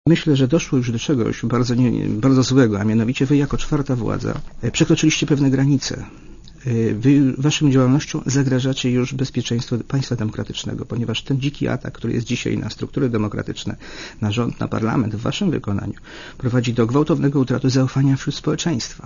Oświadczenie jest odpowiedzią byłego ministra na liczne krytyczne komentarze (w mediach, jak i w świecie polityki, także w SLD) po wywiadzie Łapińskiego dla Radia Zet z 13 maja.